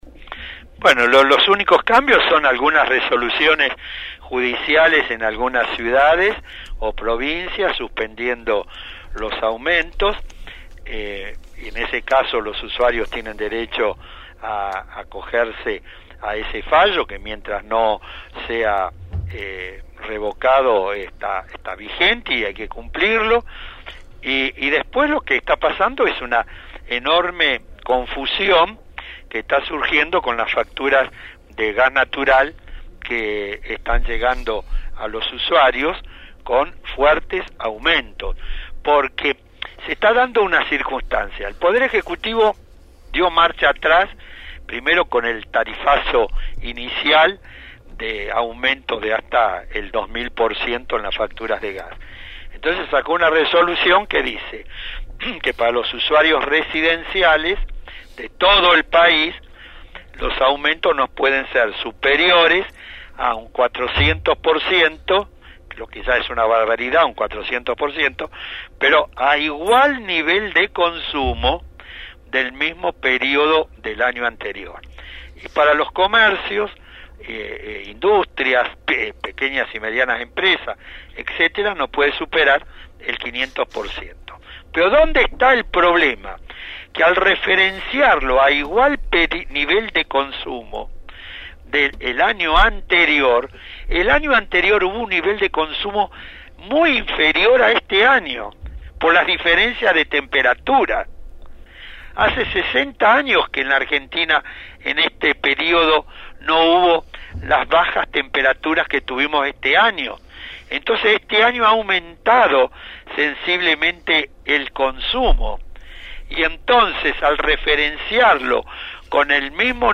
El doctor Héctor Polino, representante de «Consumidores libres», dialogó con el equipo de «El Hormiguero» sobre la jornada de protesta llevada a cabo en el día de ayer en todo el país contra los «tarifazos».